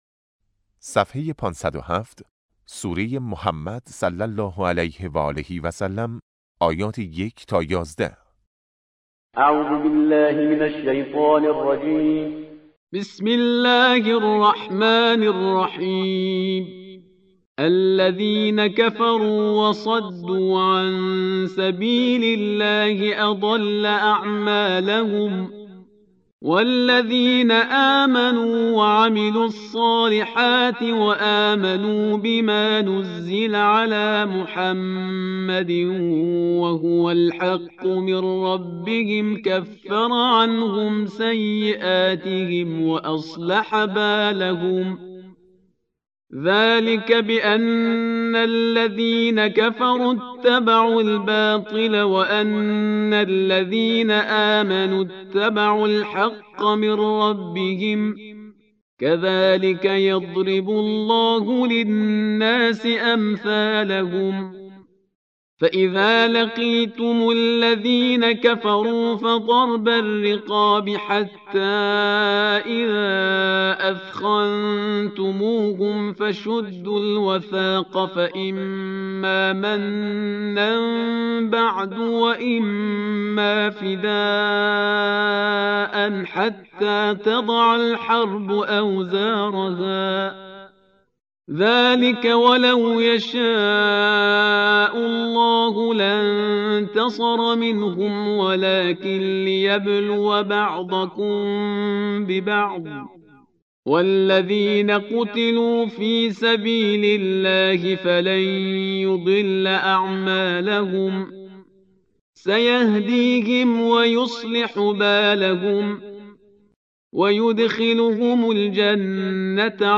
قرائت درس سوم جلسه دوم قرآن نهم